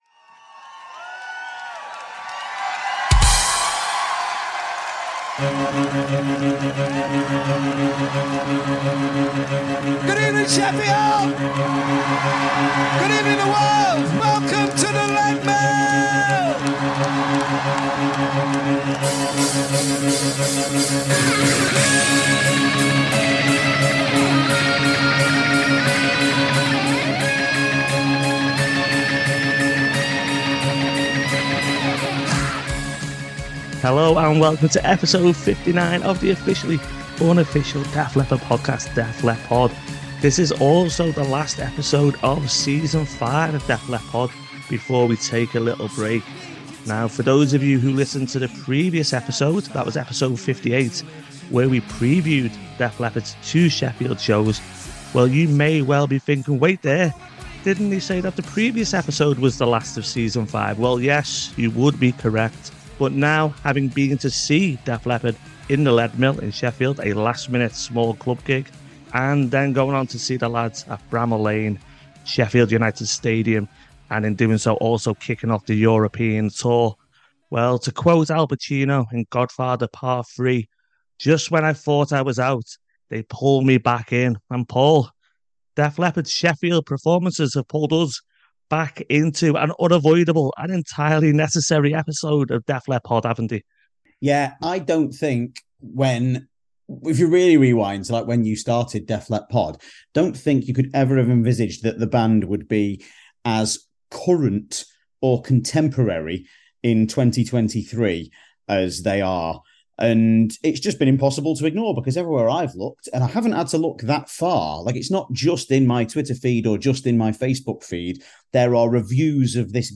Warning: Contains 'agricultural' language.